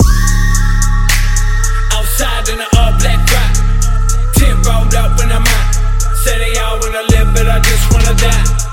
All Black Ride.wav